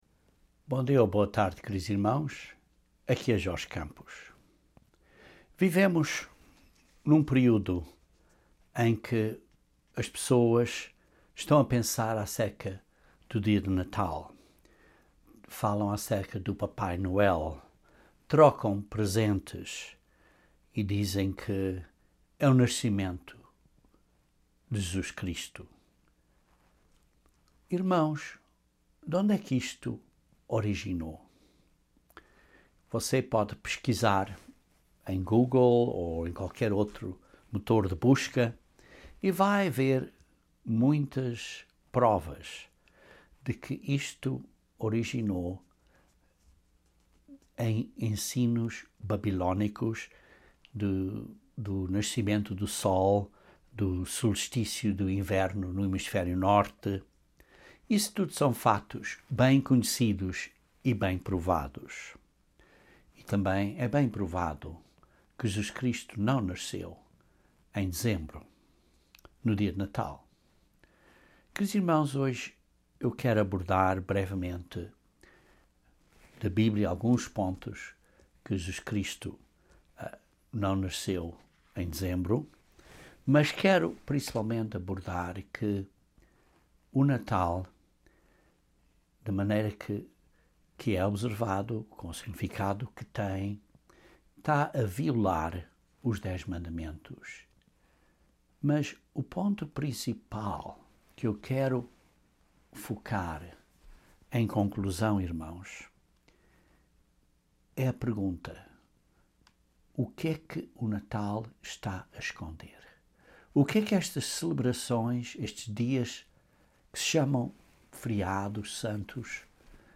Este sermão analisa como o Natal quebra os dez mandamentos e esconde o plano de salvação de Deus.